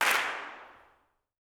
CLAPS 02.wav